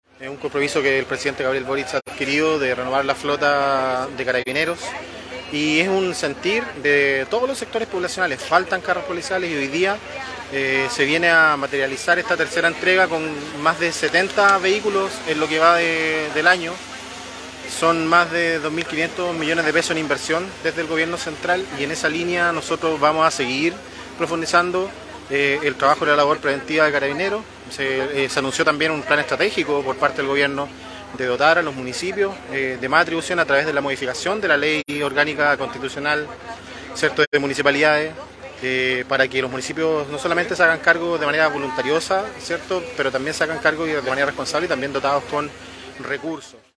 Finalmente, el delegado presidencial provincial, José Patricio Correa, afirmó que esta entrega de carros policiales viene a cumplir un compromiso del presidente Gabriel Boric en materia de seguridad.